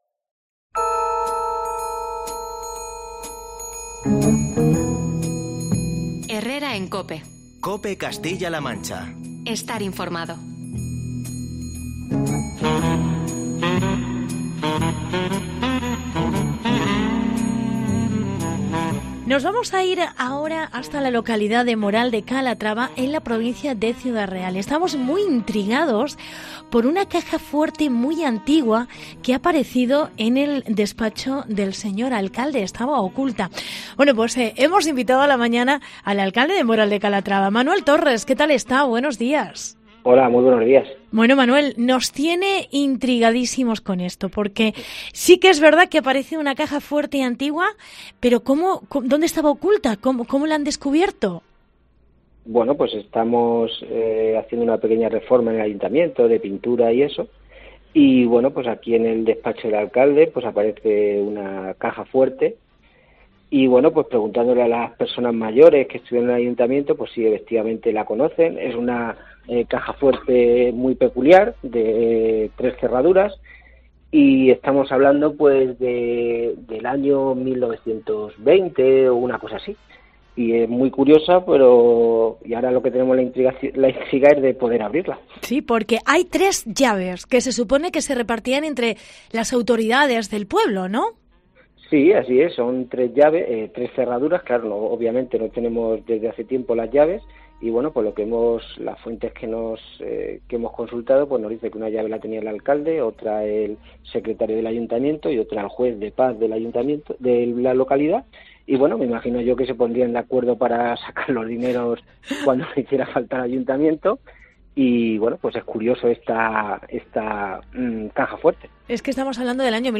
Entrevista Manuel Torres. Alcalde de Moral de Calatrava